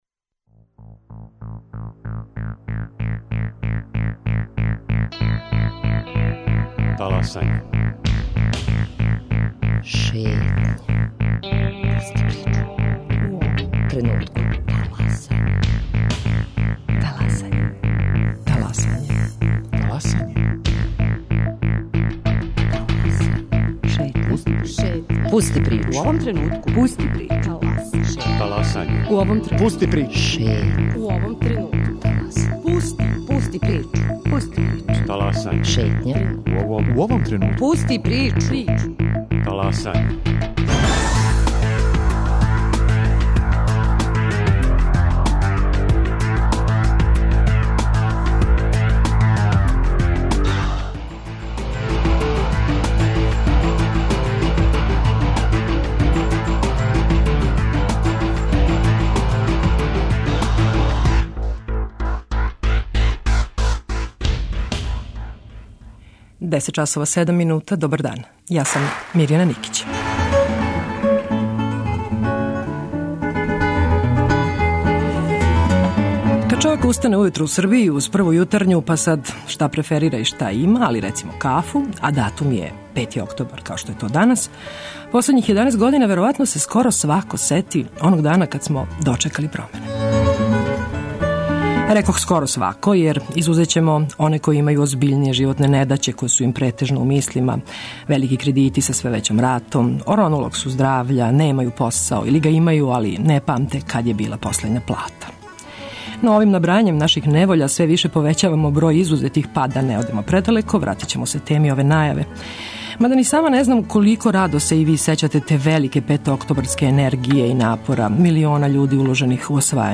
Радио Београд 1. 10.00